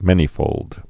(mĕnē-fōld)